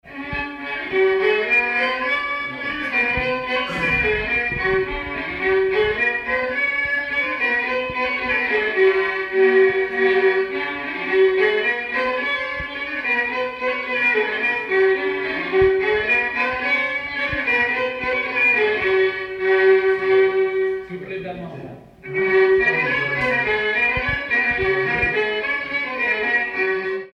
danse : marche
danse : baion
circonstance : bal, dancerie
Pièce musicale inédite